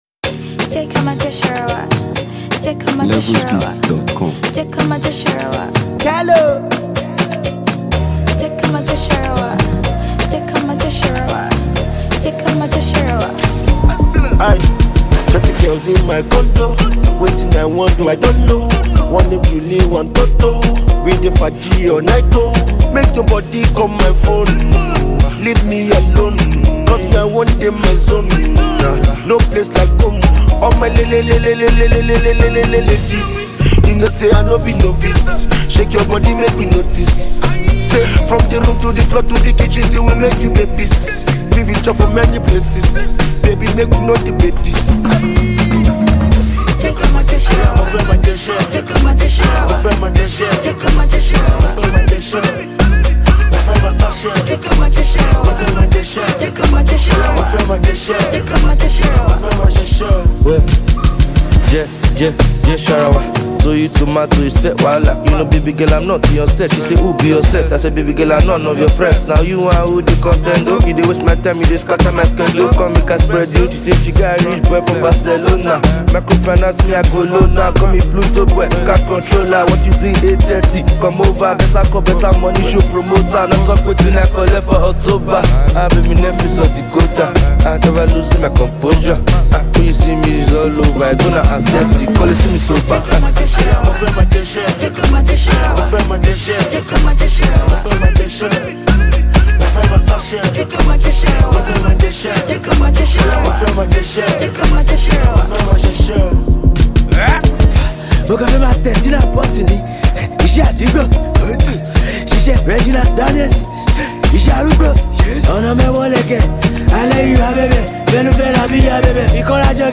a multifaceted Nigerian afrobeat singer
This energetic masterpiece
a highly skilled street-hop artist
whose unique vocals add depth and flavor to the record.